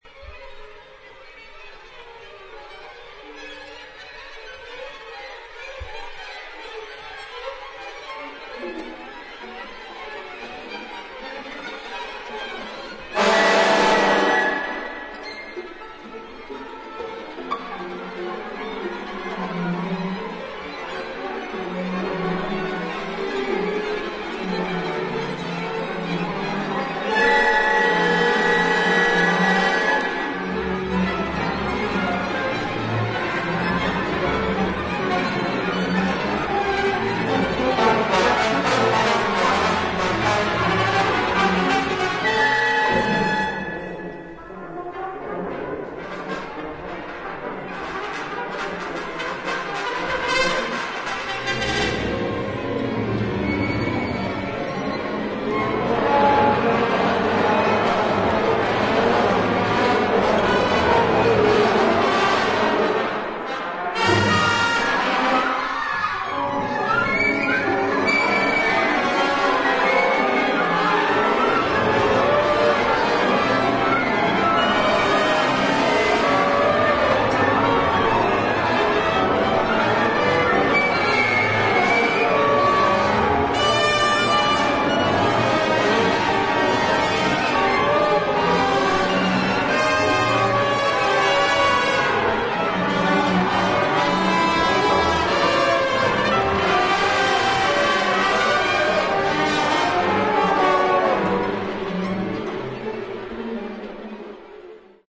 Für Orchester